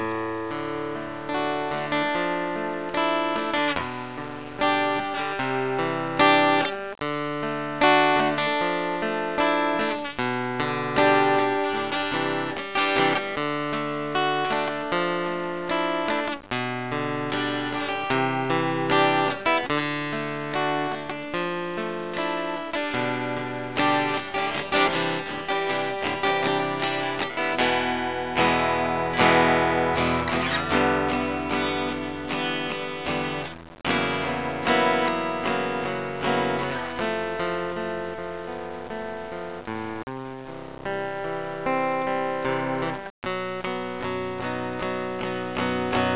It has a "fruity" tone, and is wired for sound.